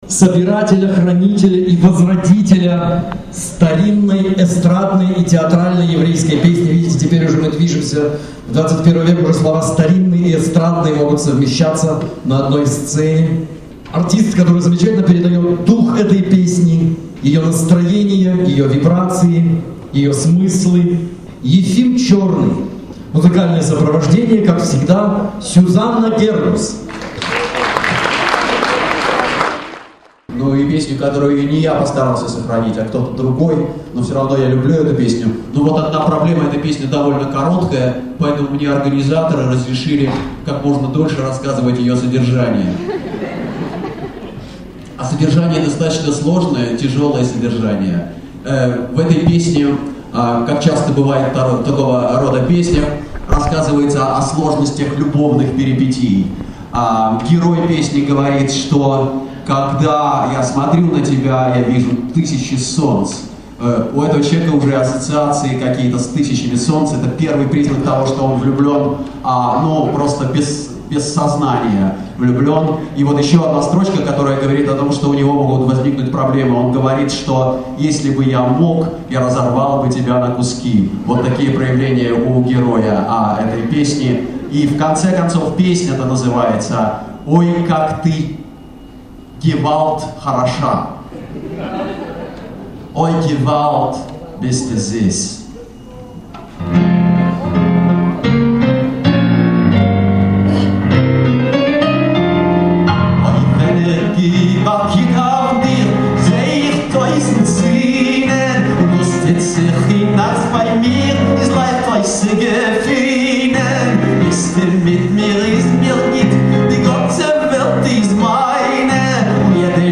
Четвертый международный фестиваль еврейской музыки
фестиваль клезмерской музыки